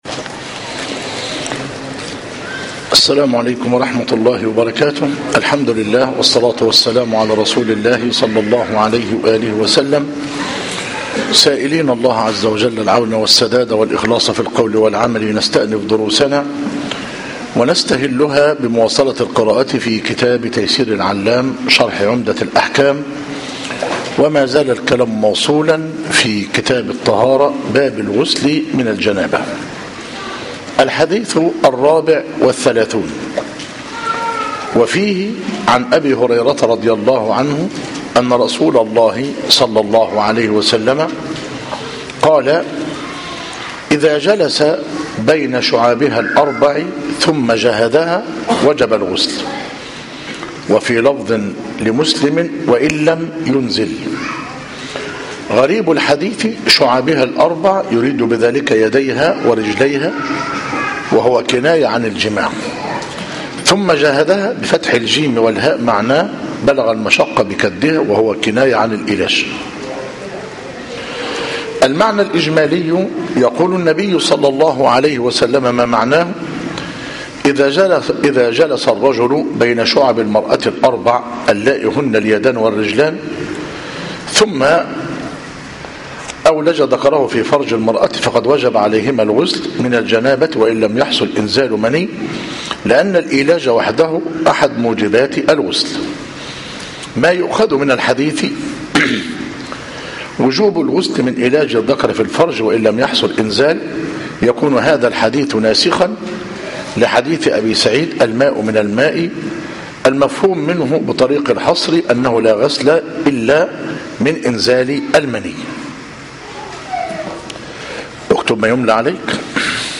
028 تيسير العلام شرح عمدة الأحكام (تابع باب غسل الجنابة- الحديث الرابع و الثلاثون) مسجد الفاروق -مدينة العاشر من رمضان -محافظة الشرقية